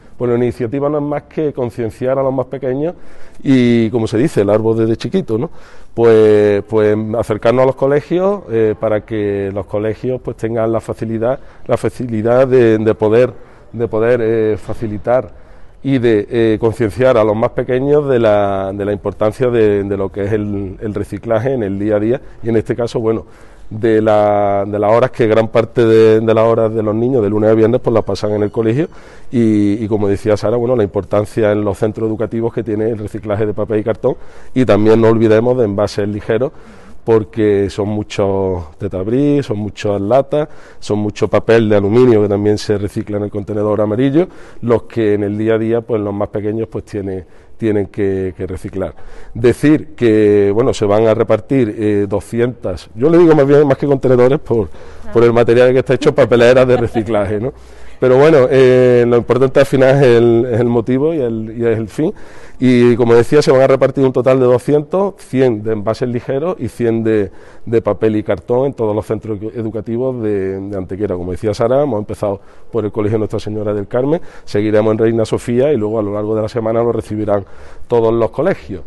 Cortes de voz